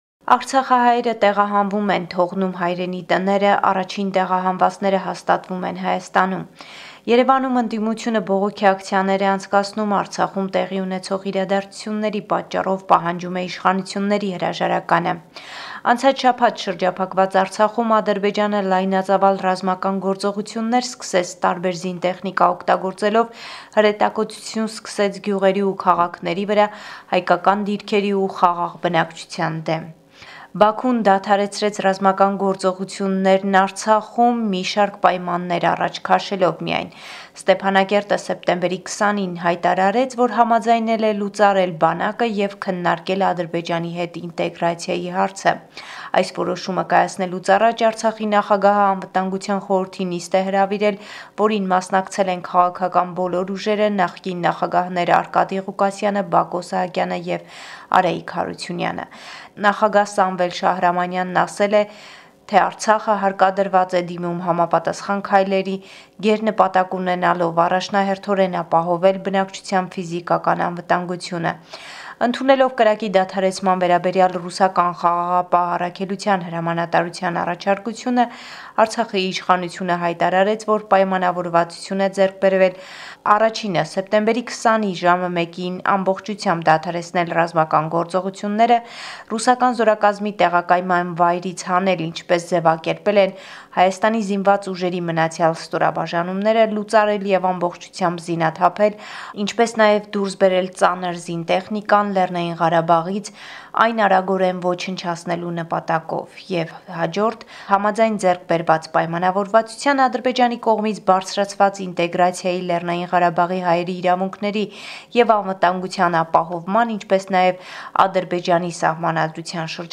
Latest news from Armenia, Artsakh and the Diaspora